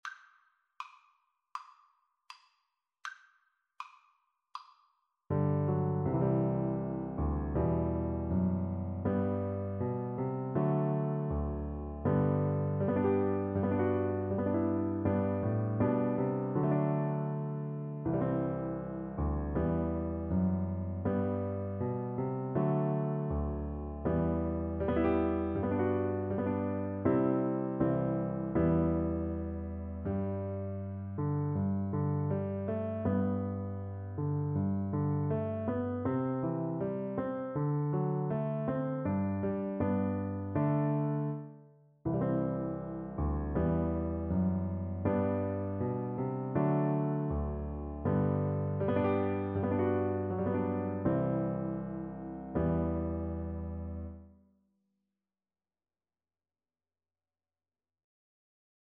4/4 (View more 4/4 Music)
Andante Espressivo = c. 80